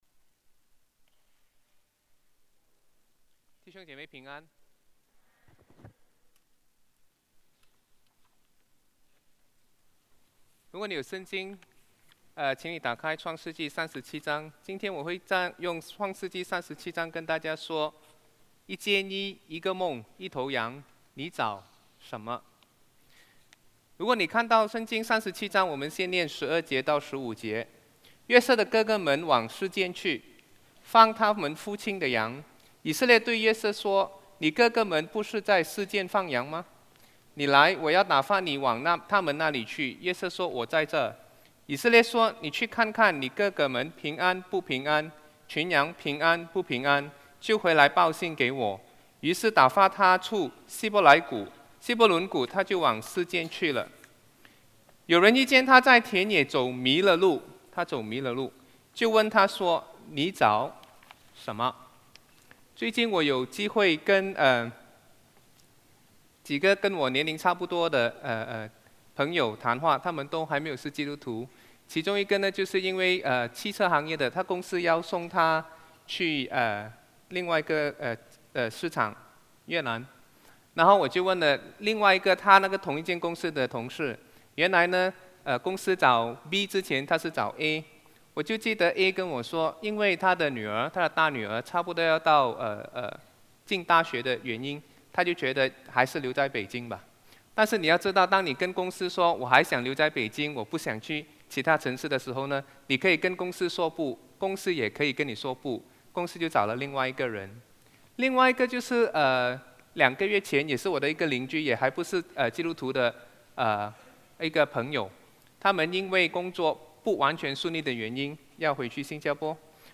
主日证道 |  一件衣，一个梦，一头羊：你找什么？